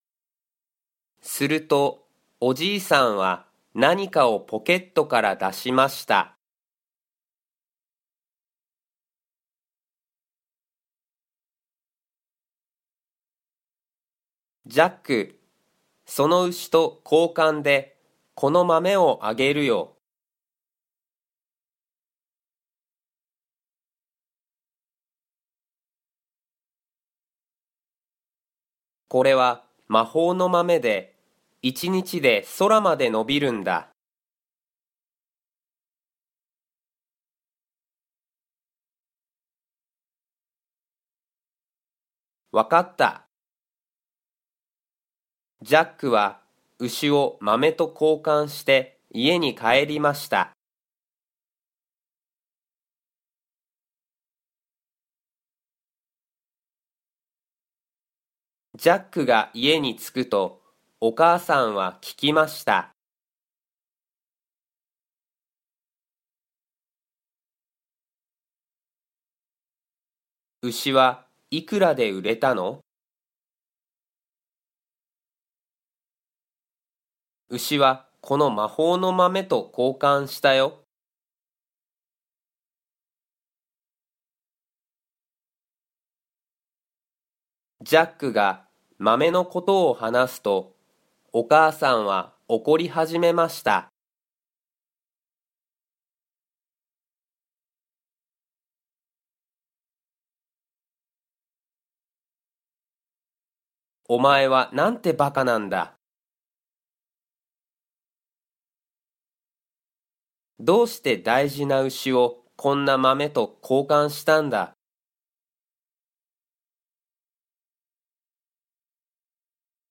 Japanese Graded Readers: Fairy Tales and Short Stories with Read-aloud Method
Slow Speed
Natural Speed